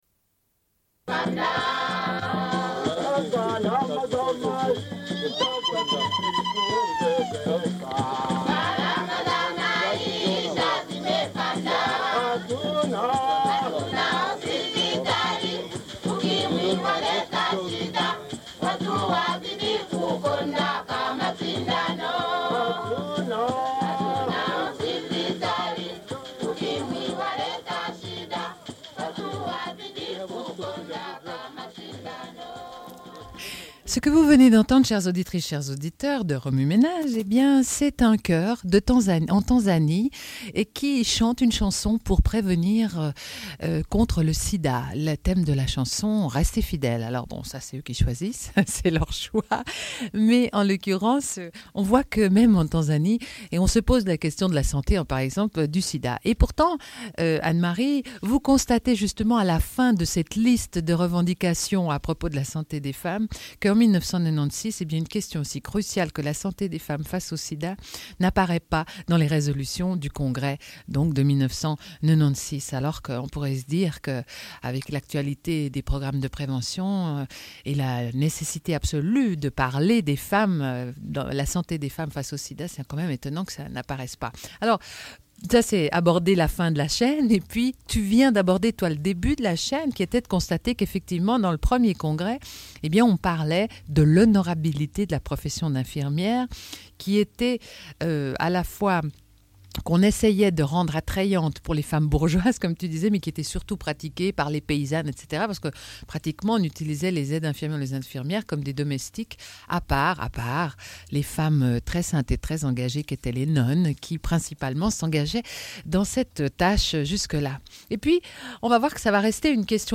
Le début de l'émission est manquant.
Une cassette audio, face A
Radio